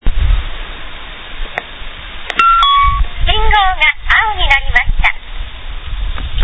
この押しボタン式信号は音声案内システムです。
このファイルは南北方向の歩行者用信号が青になったときの音声案内です。声は女声です。
hosoe-general-office_vgd-w.MP3